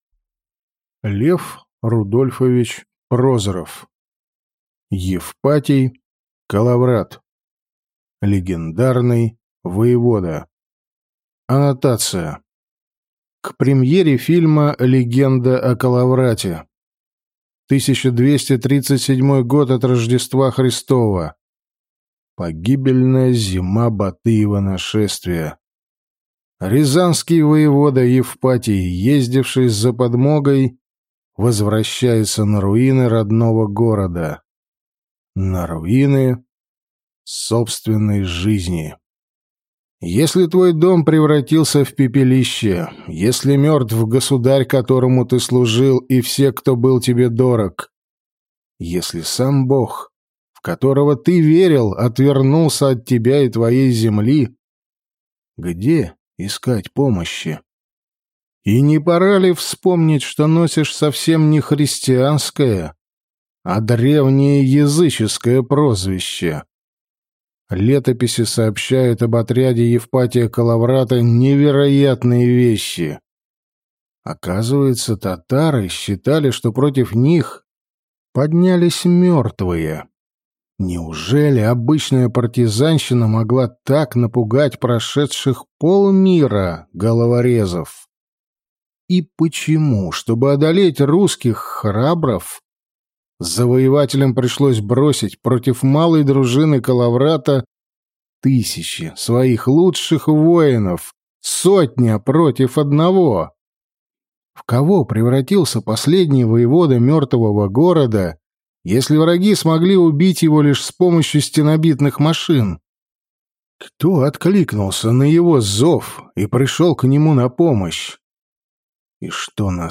Аудиокнига Евпатий Коловрат. Легендарный воевода | Библиотека аудиокниг